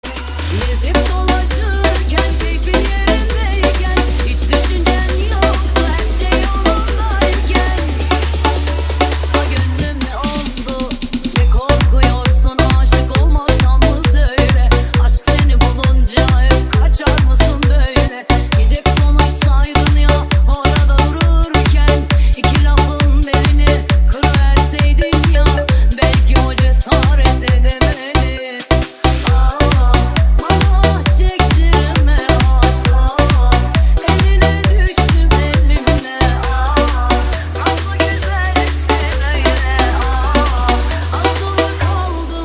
Kategori Elektronik